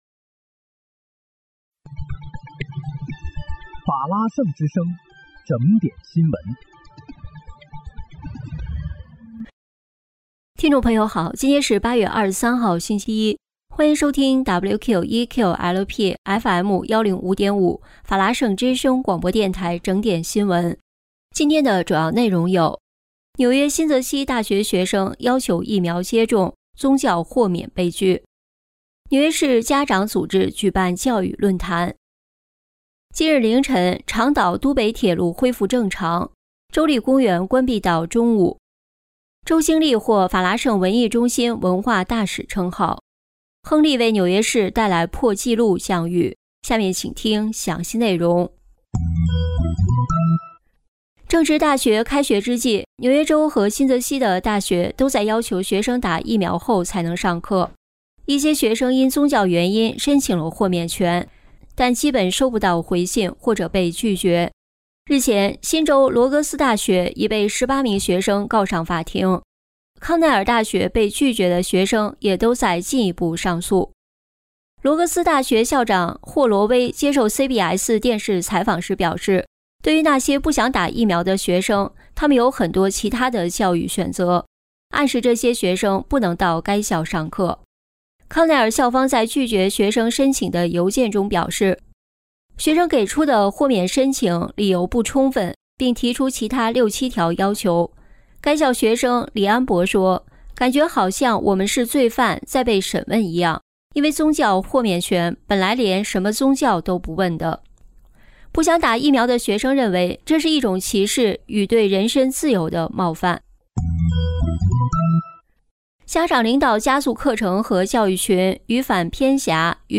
8月23日（星期一）纽约整点新闻